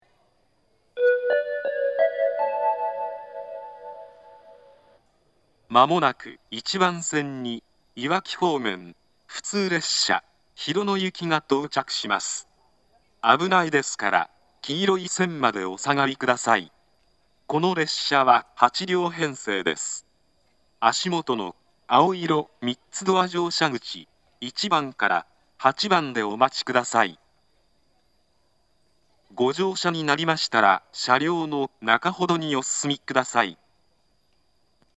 この時同時に自動放送も更新され、東海道詳細型放送から合成音声による放送になりました。
接近放送 いわき方面 普通列車 広野行き（8両編成）の接近放送です。